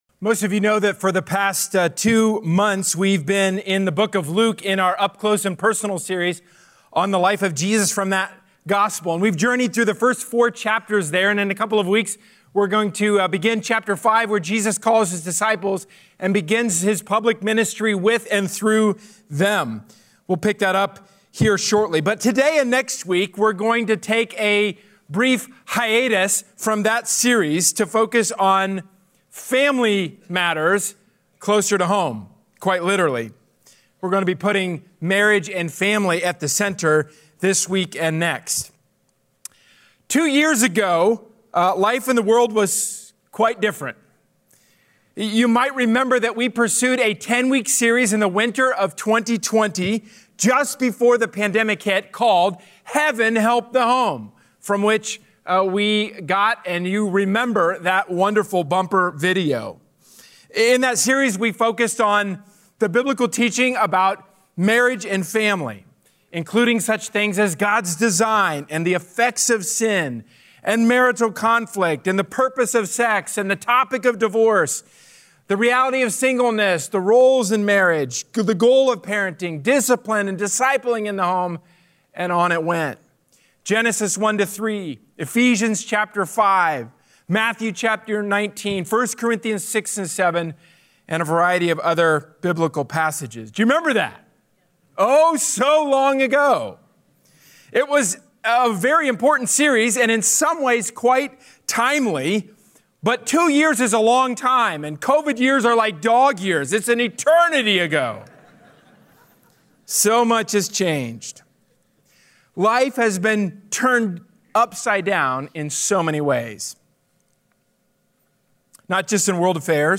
A sermon from the series "Heaven, Help the Home!." Parenting calls us to connect our children with the ultimate Parent in whom is life.